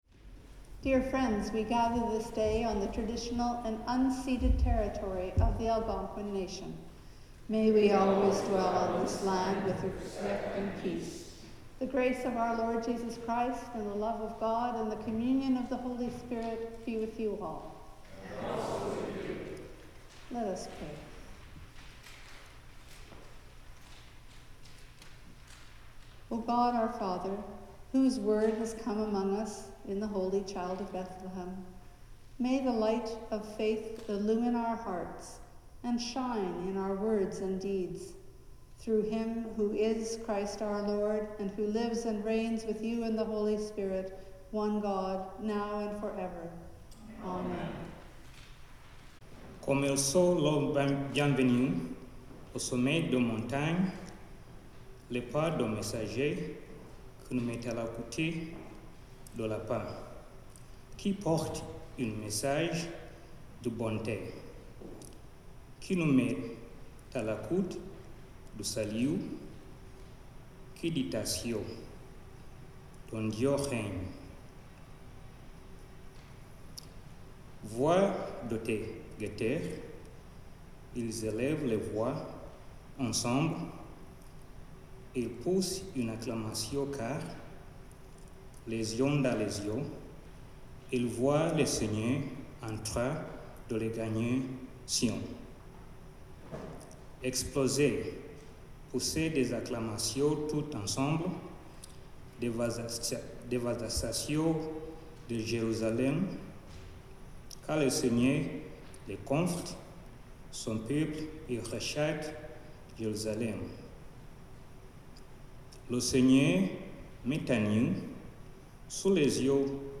Sermon
Carol Singing